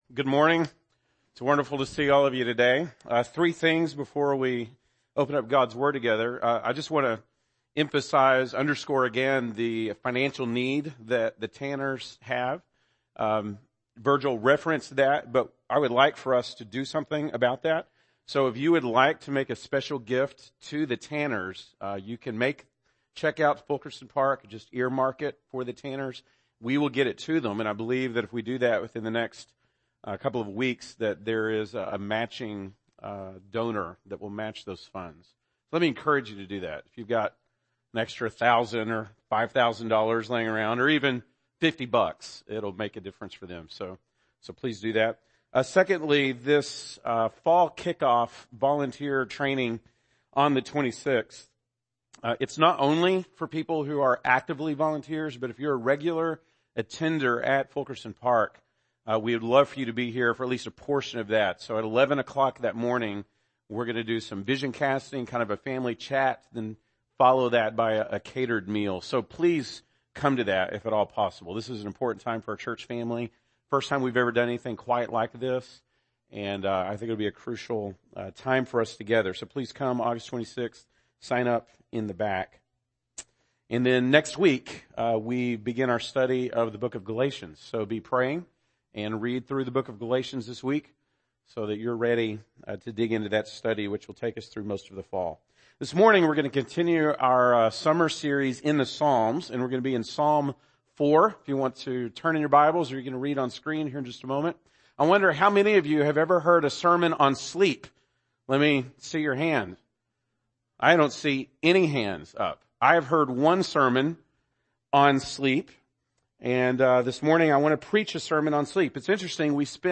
August 13, 2017 (Sunday Morning)